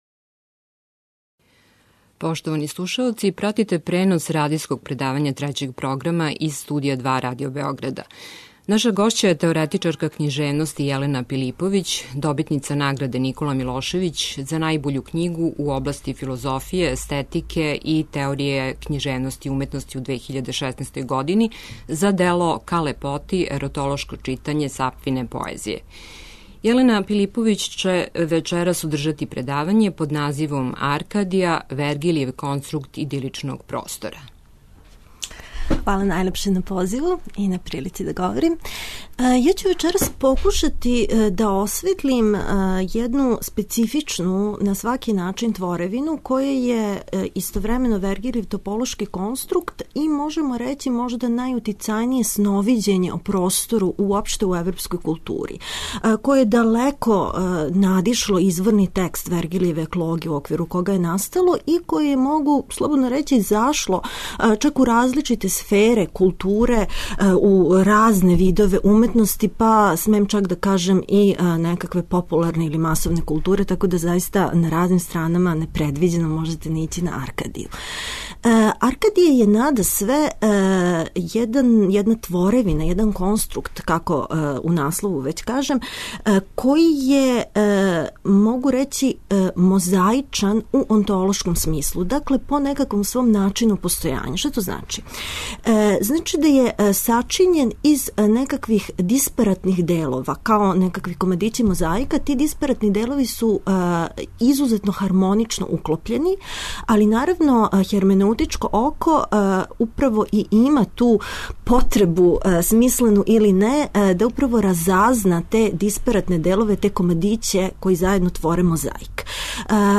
радијско предавање
које уживо емитујемо из Студија 2 Радио Београда.
преузми : 21.64 MB Радијска предавања, Дијалози Autor: Трећи програм Из Студија 6 директно преносимо јавна радијска предавања.